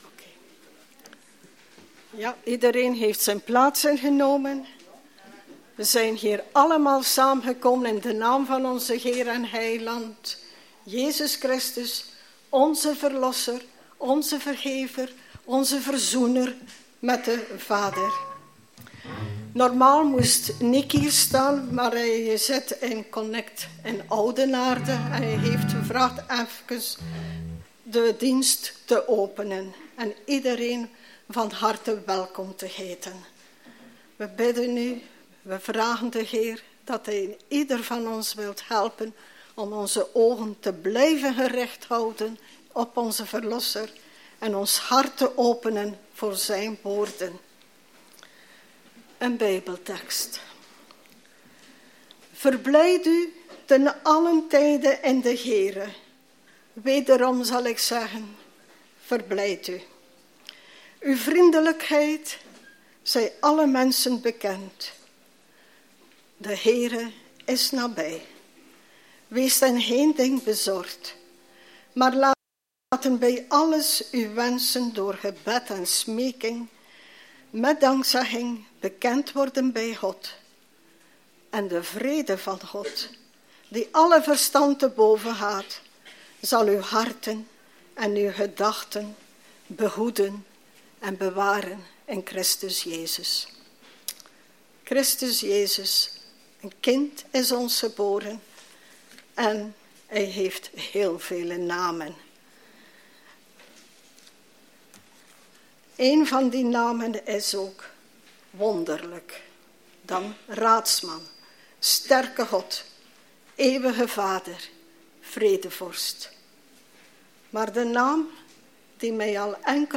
Aantekeningen bij de preek